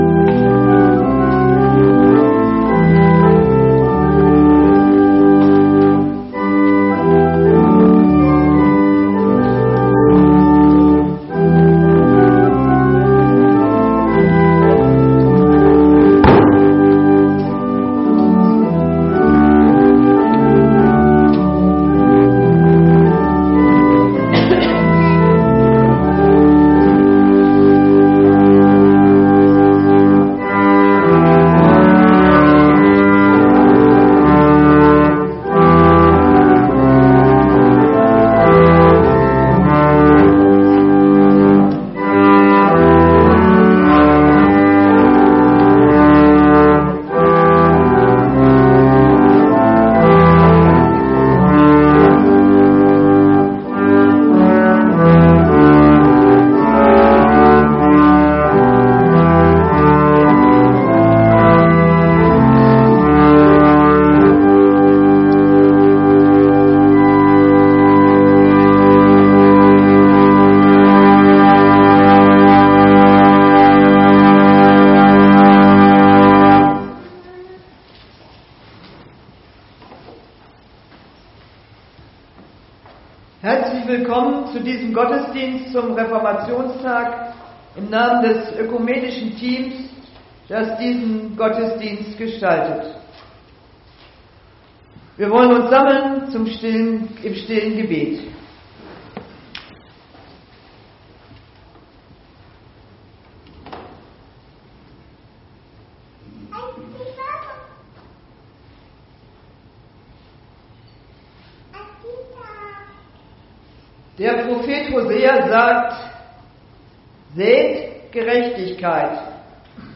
Gottesdienst Reformationstag 31.10.2025 | Evangelisch-altreformierte Kirchengemeinde Laar
Wir laden ein, folgende Lieder aus dem Evangelischen Gesangbuch mitzusingen: Lied 177, 2, Lied 432, 1 – 3, Lied 419, 1 – 5, Psalm 98, 1 – 4, Lied 667, 1 – 5, Lied 362, 1 + 2, Psalm 68, 1 + 5 + 6